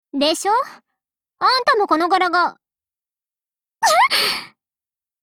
贡献 ） 协议：Copyright，人物： 碧蓝航线:希佩尔海军上将语音 您不可以覆盖此文件。